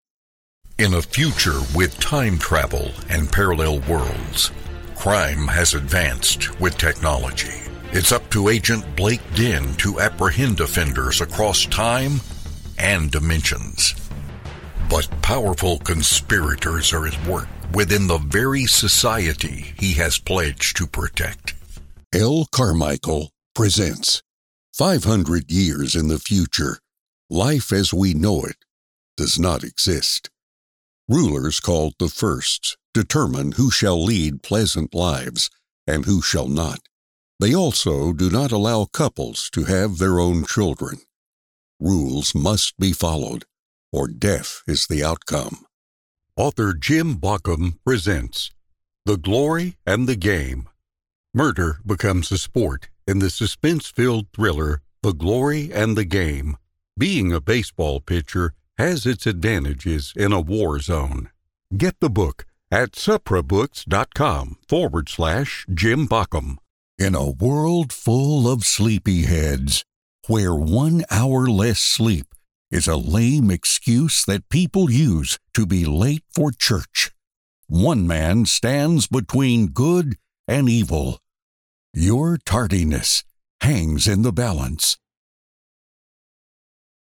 Male
Adult (30-50), Older Sound (50+)
Book Trailers
All our voice actors have professional broadcast quality recording studios.
1020TRAILER_DEMOS.mp3